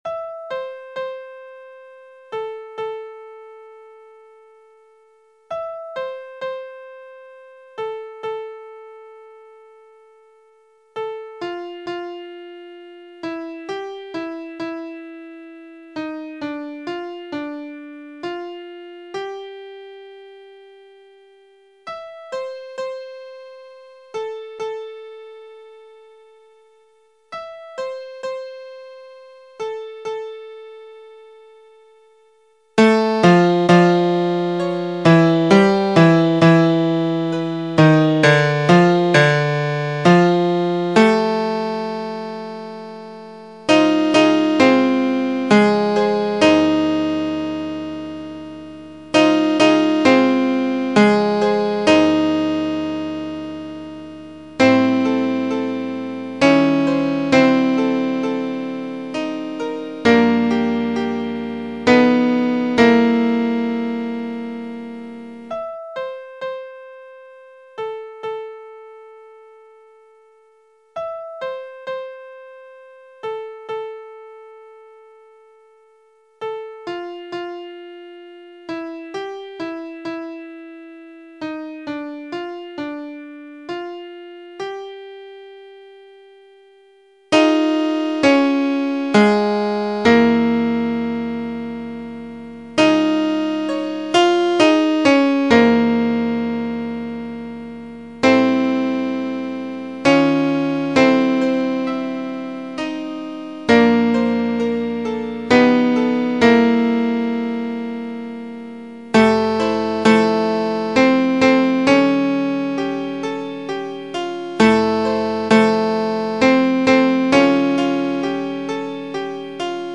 Christmas Time is Here (arr. Alexander)      Your part emphasized:     Sop 1   Sop 2
Tenor 1   Tenor 2     Bass 1   Bass 2